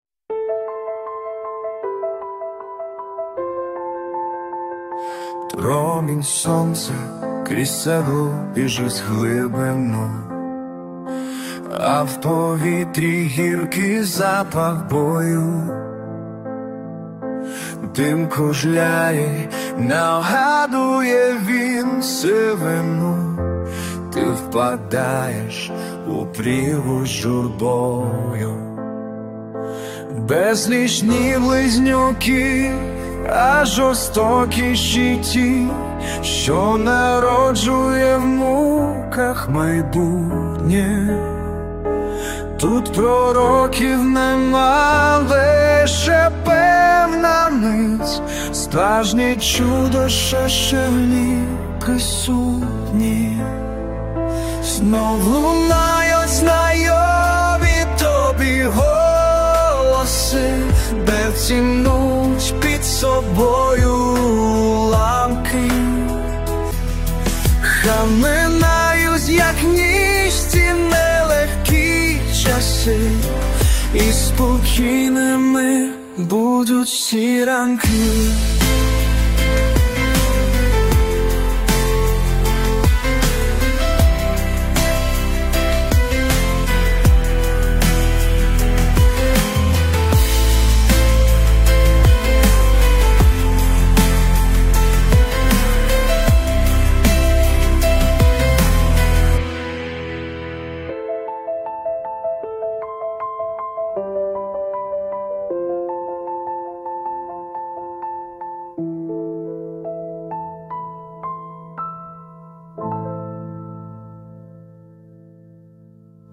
Слова - автора, музика - підтримка ШІ.
СТИЛЬОВІ ЖАНРИ: Ліричний